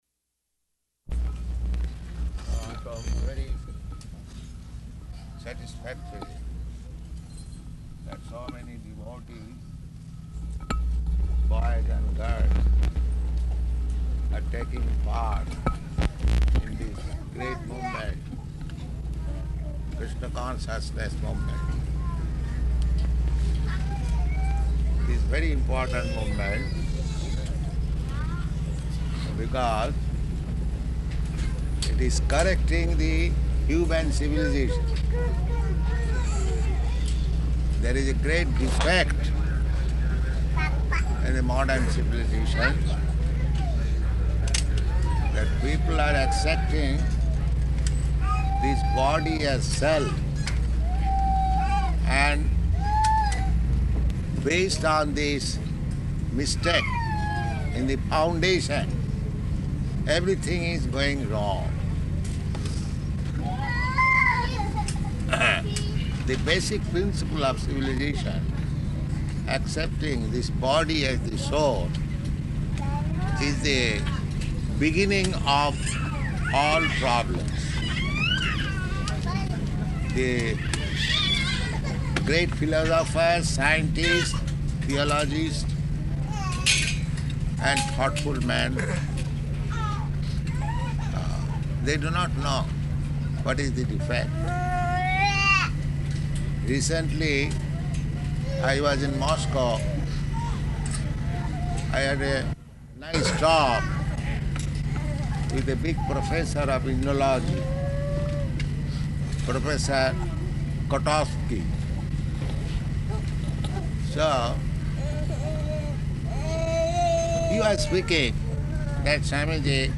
Arrival Address [Airport]
Location: Detroit
Initiation LectureLecture